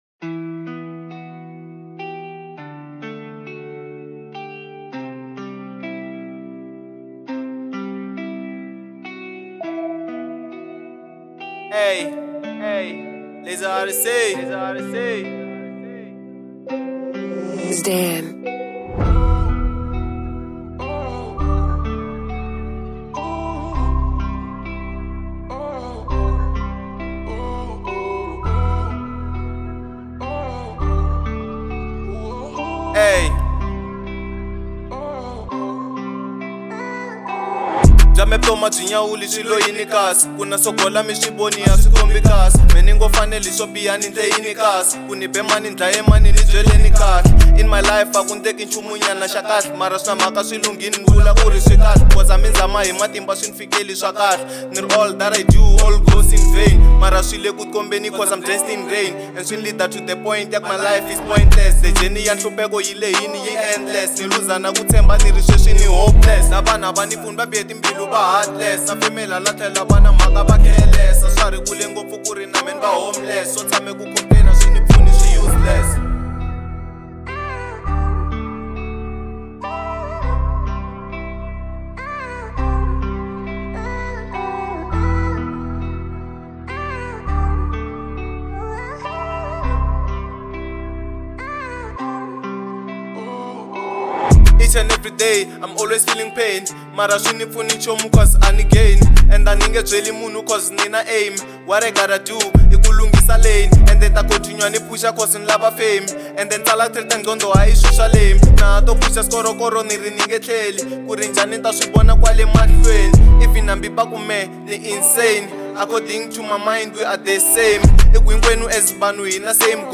03:08 Genre : Trap Size